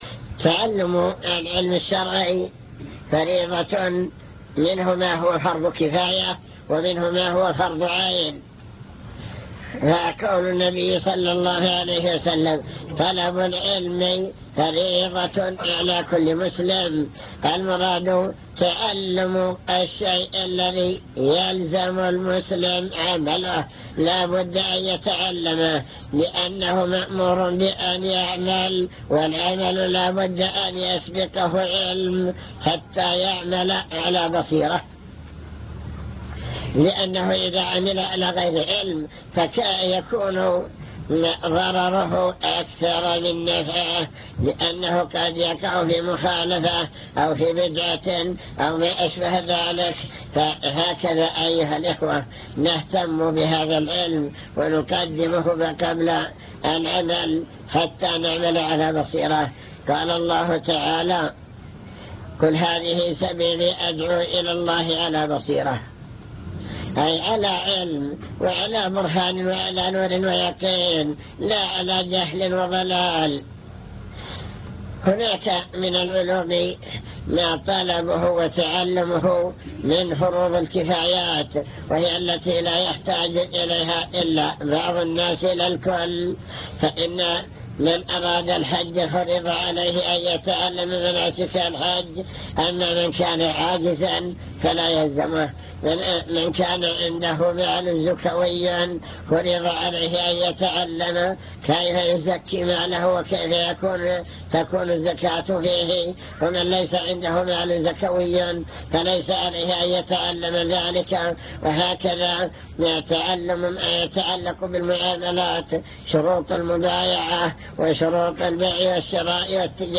المكتبة الصوتية  تسجيلات - محاضرات ودروس  محاضرات عن طلب العلم وفضل العلماء بحث في: أهم المسائل التي يجب على المسلم تعلمها